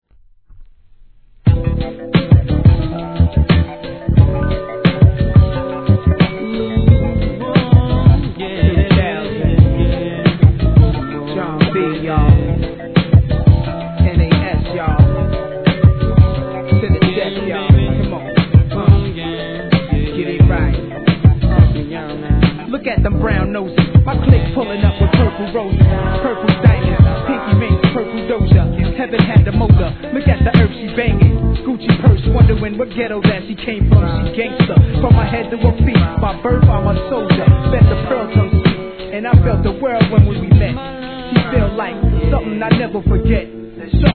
1. HIP HOP/R&B
なんとも心地よいスムースダンサー人気曲♪